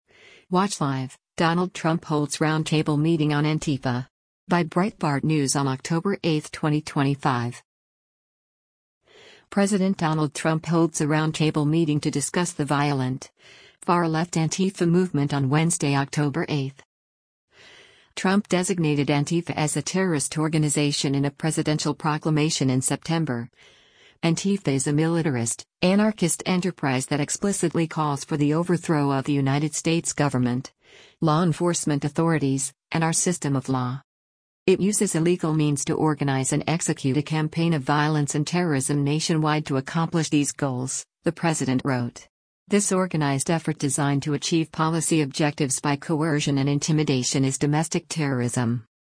President Donald Trump holds a roundtable meeting to discuss the violent, far-left Antifa movement on Wednesday, October 8.